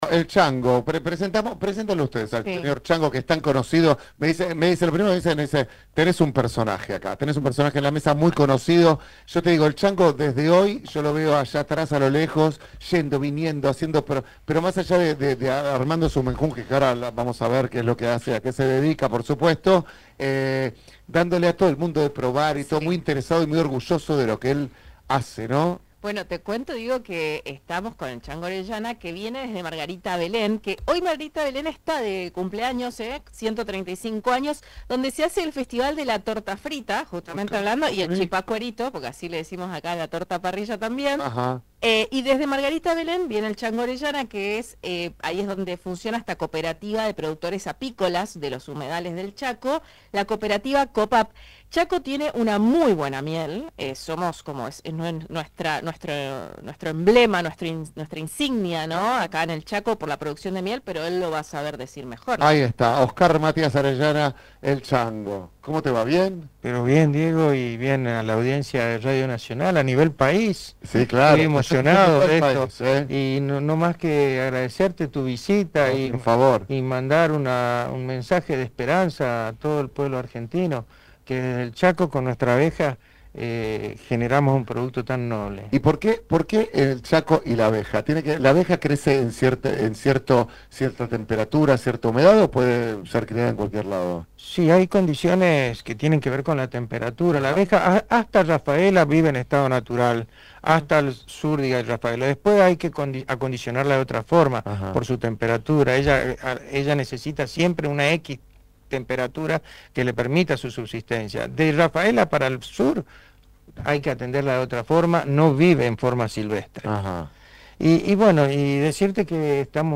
visitó el estudio de Radio Nacional Resistencia